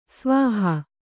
♪ svāhā